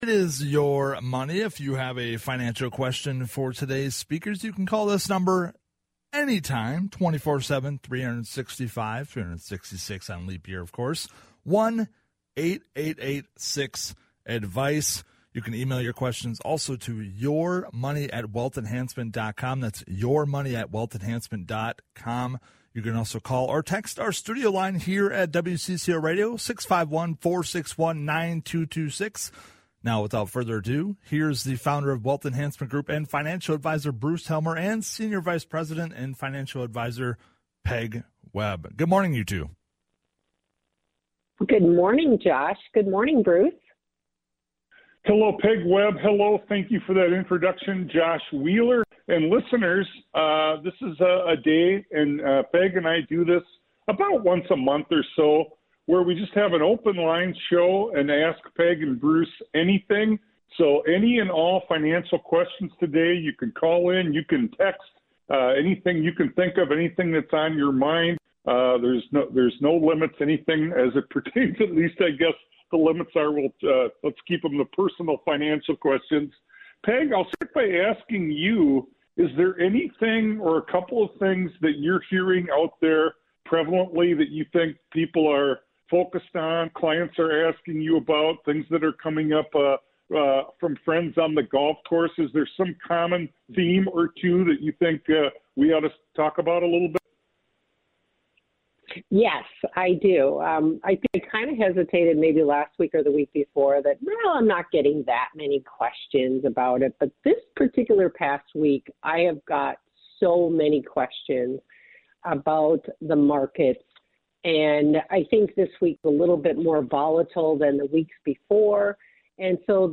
1 Chatting with Appeals Court Judge Valerie Zachary 45:17 Play Pause 5h ago 45:17 Play Pause Play later Play later Lists Like Liked 45:17 North Carolina Senator Vickie Sawyer hosts a weekly show about the legislation in North Carolina on Fridays at 11 am on WSIC.